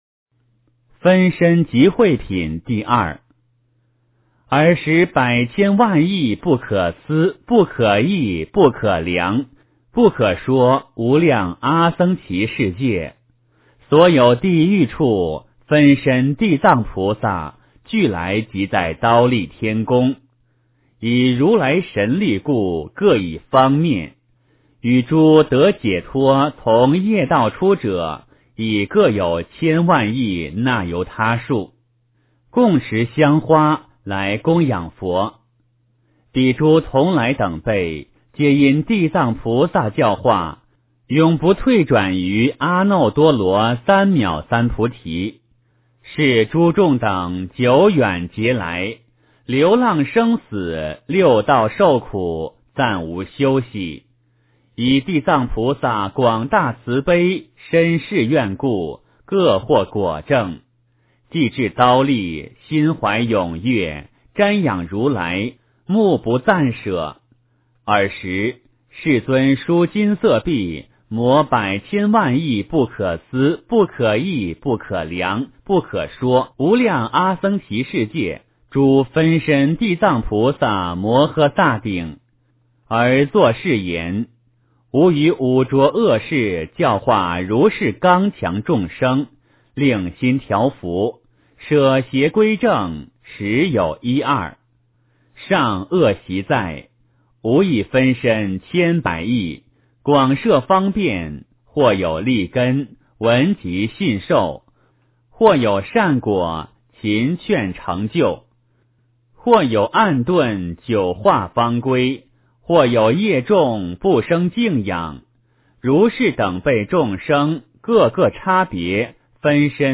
地藏经-分身集会品第二 诵经 地藏经-分身集会品第二--佛经 点我： 标签: 佛音 诵经 佛教音乐 返回列表 上一篇： 无量寿经-中 下一篇： 地藏经-观众生业缘品第三 相关文章 祈愿--海涛法师 祈愿--海涛法师...